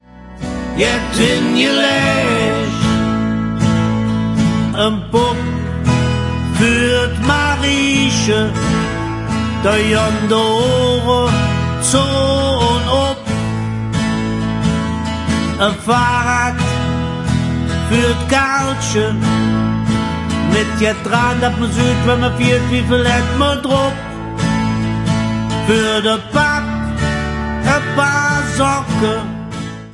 Advents- und Weihnachtsleedcher in Kölner Mundart